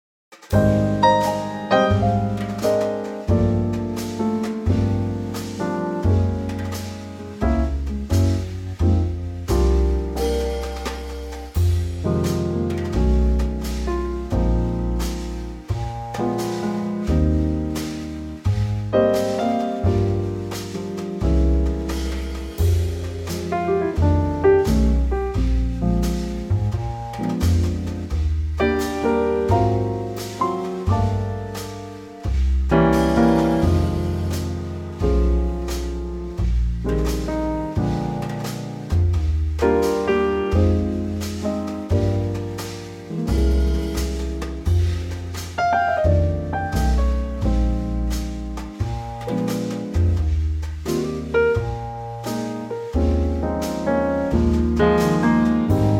Unique Backing Tracks
key - Eb - vocal range - Bb to D
Wonderful Trio arrangement